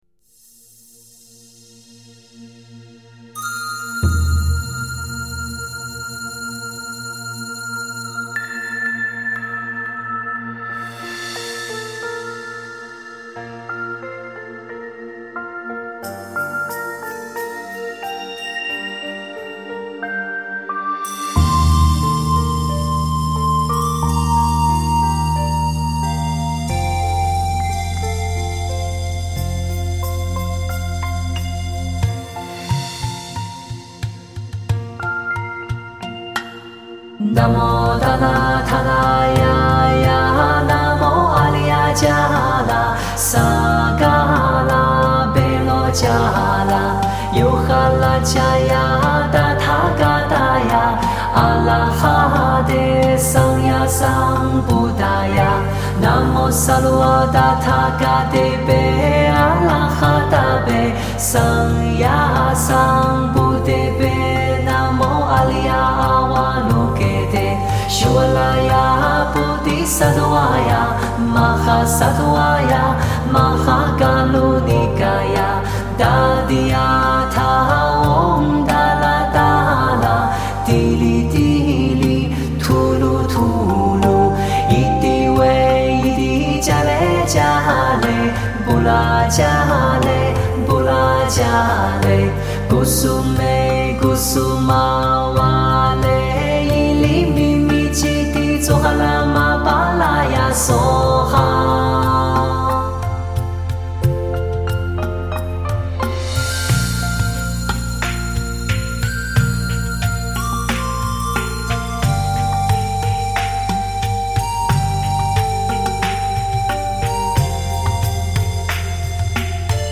佛音 诵经 佛教音乐 返回列表 上一篇： 光明真言 下一篇： 般若波罗密多心经 相关文章 普佛(代晚课